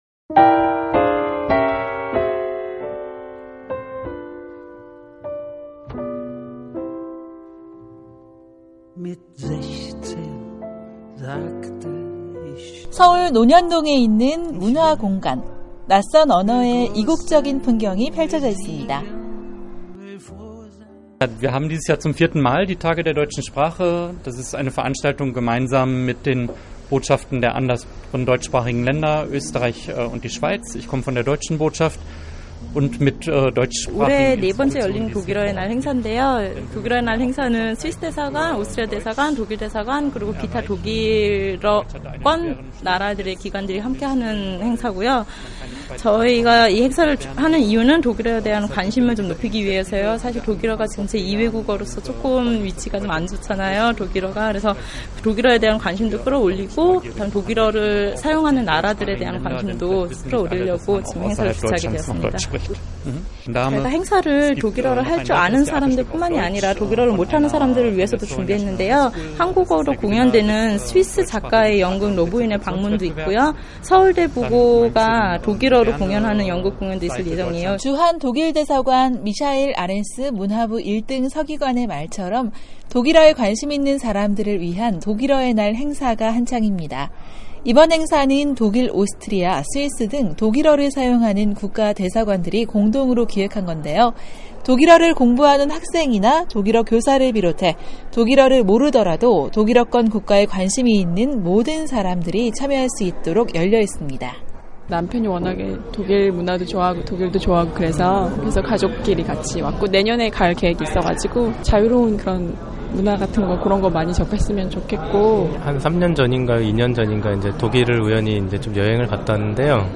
기자가 전해드립니다.